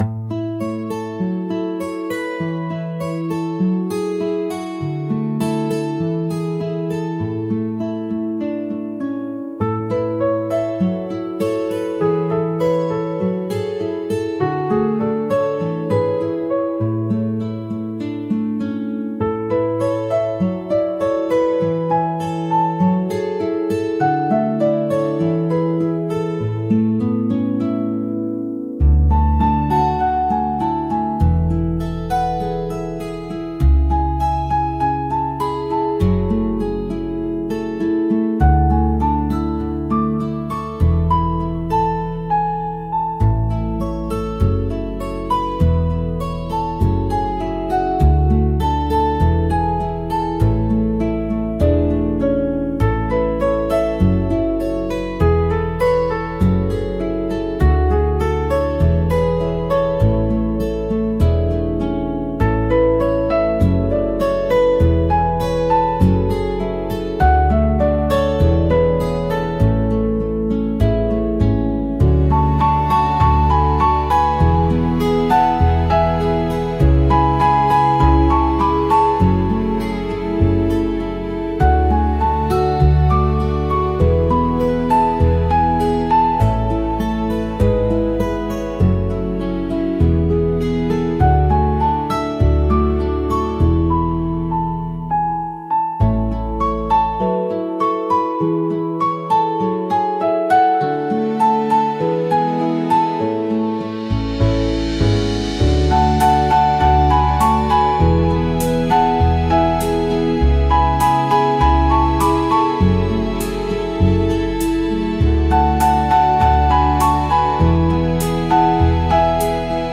フリーBGM 🎶 やさしいアコースティックの音色が日常にそっと寄り添う、ライフスタイル系にぴったりのBGMです。